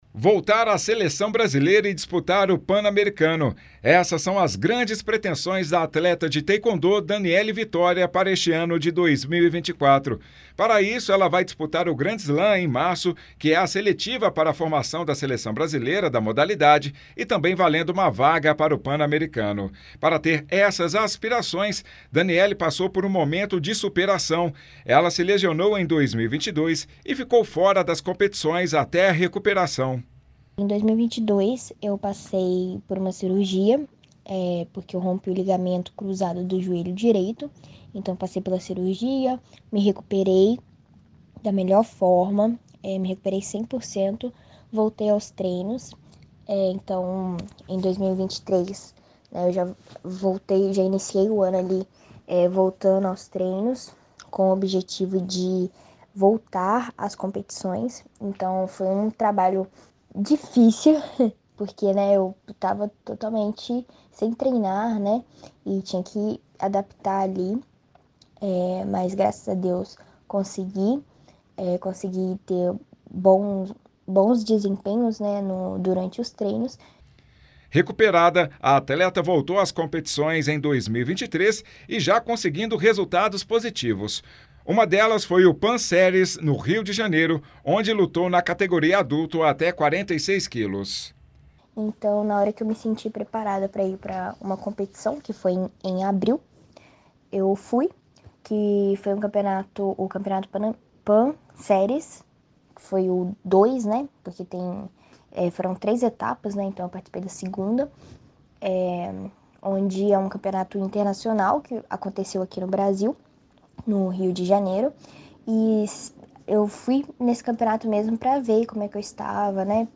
Ela falou sobre os planos para este ano à Itatiaia.